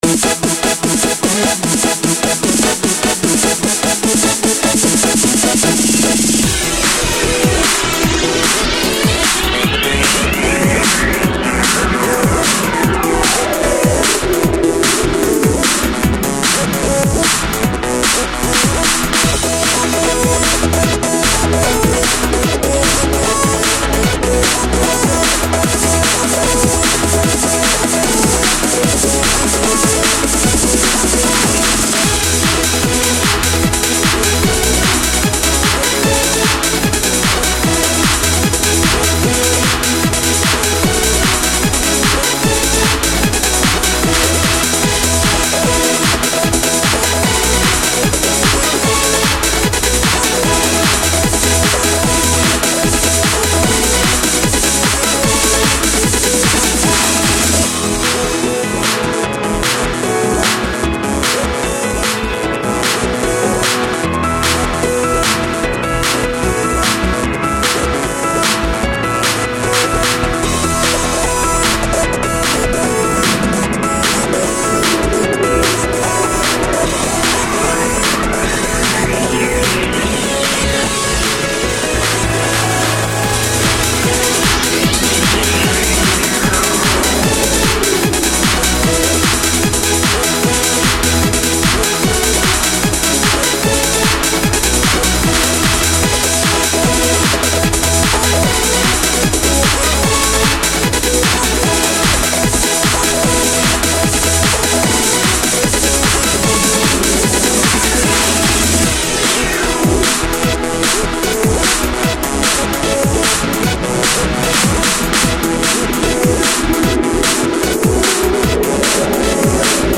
Music / Techno
Apparently something with a catchy beat :P